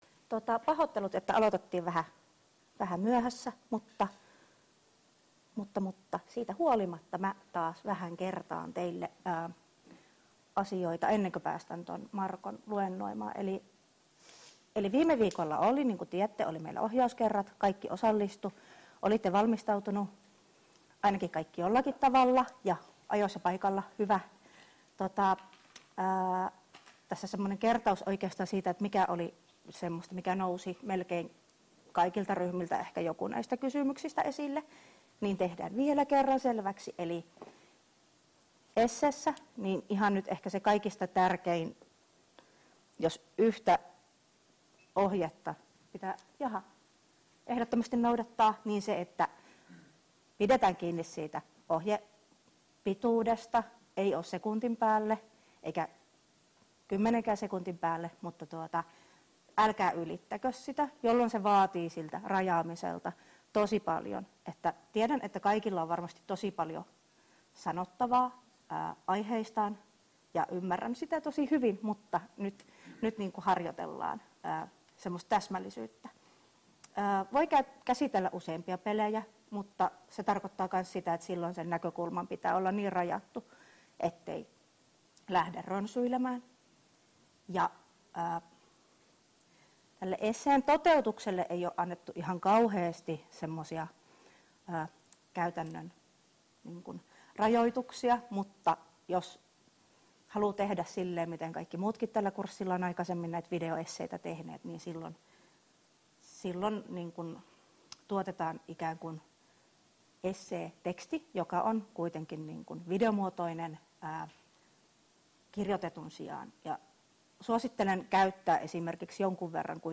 Luento 27.9.2022 — Moniviestin